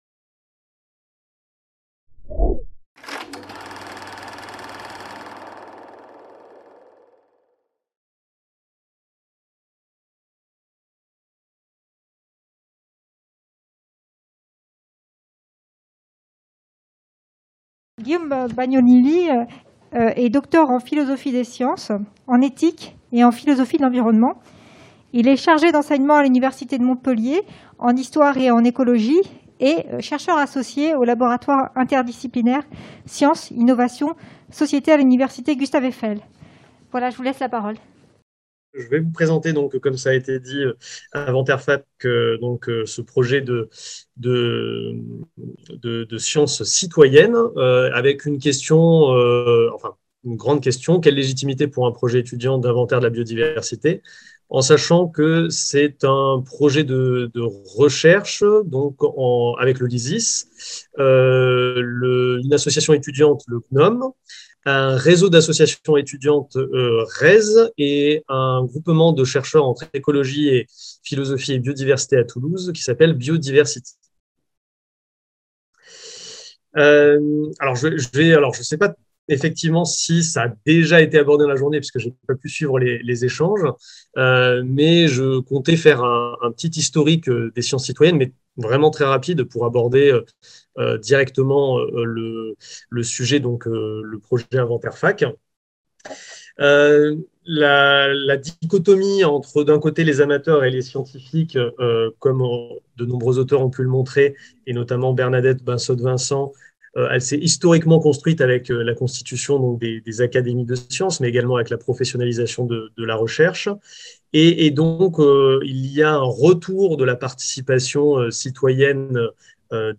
Sociétés savantes, pratiques naturalistes et nature en ville (XIXe-XXIe siècles) Journée d'étude